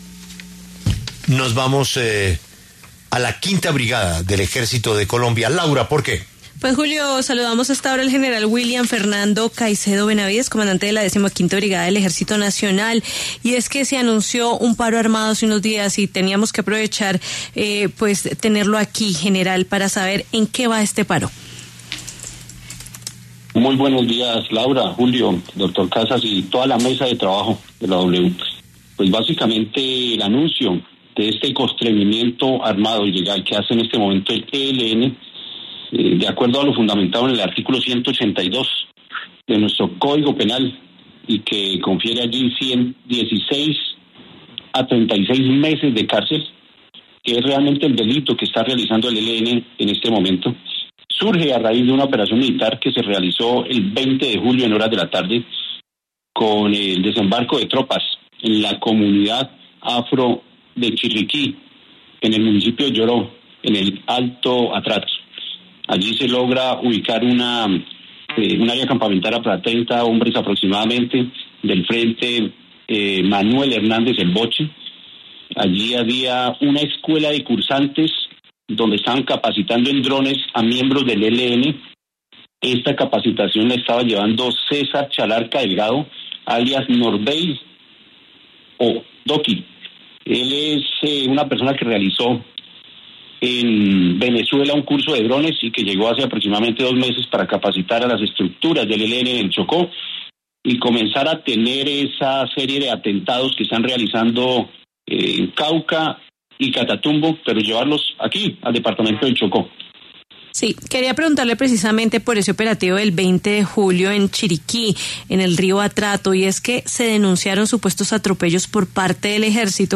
El general William Fernando Caicedo Benavidez, comandante de la Décima Quinta Brigada del Ejército Nacional, se refirió en La W al operativo adelantado en la comunidad de Chiriquí, en el río Atrato.